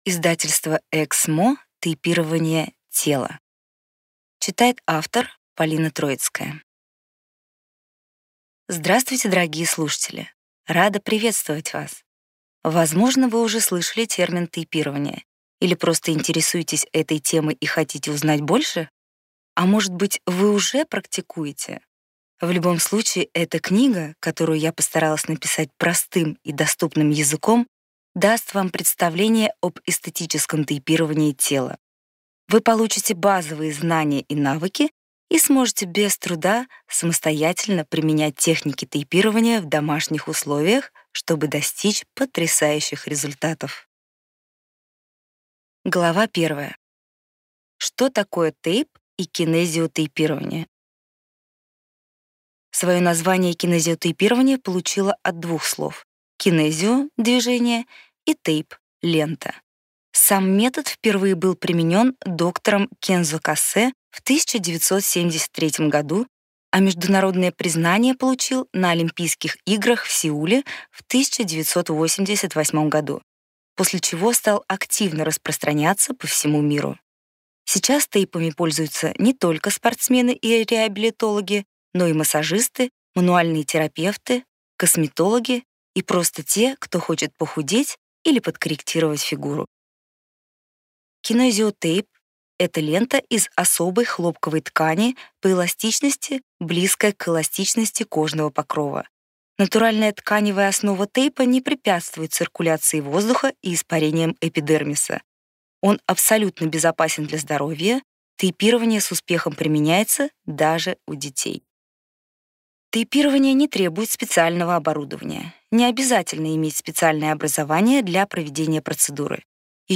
Аудиокнига Тейпирование тела. Как избавиться от проблемных зон без спорта и диет | Библиотека аудиокниг